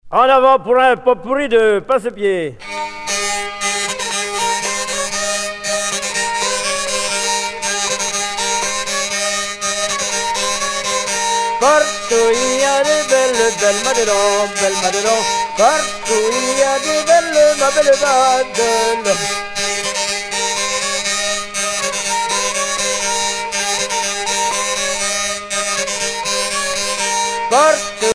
danse : passepied
Sonneurs de vielle traditionnels en Bretagne